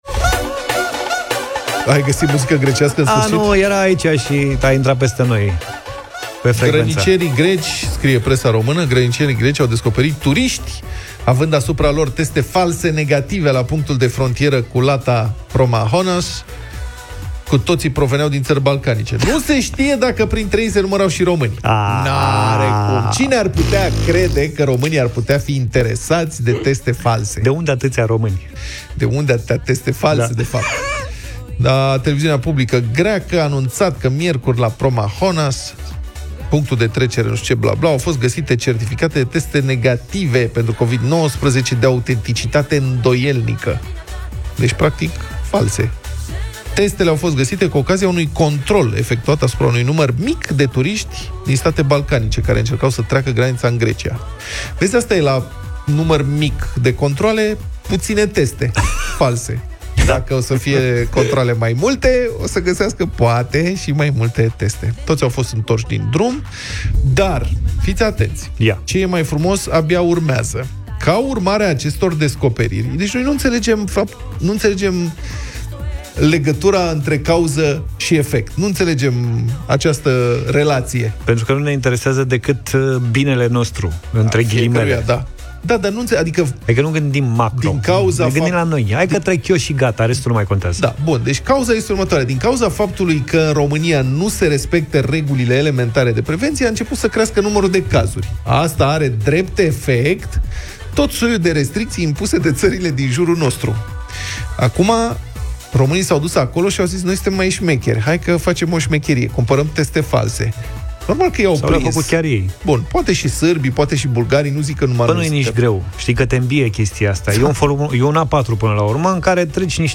au vorbit despre acest subiect în Deșteptarea